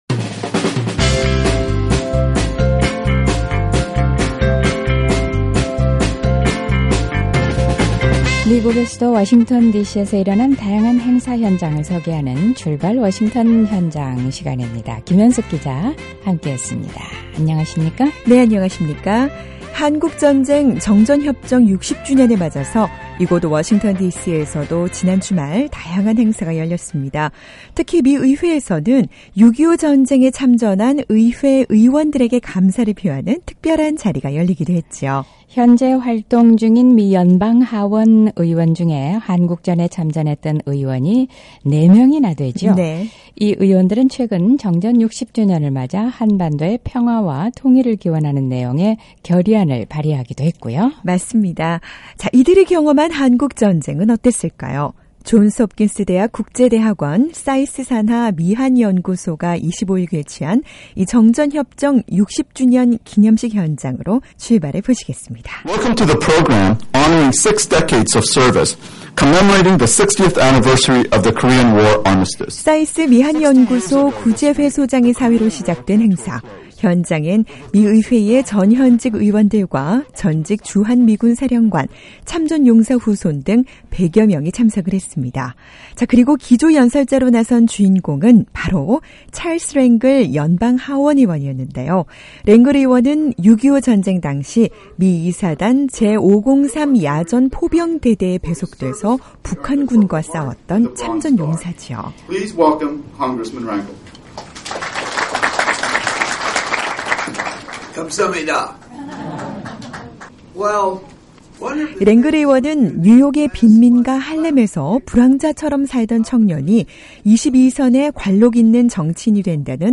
한국전 정전 60주년 의회 기념식 현장으로 출발해보시죠.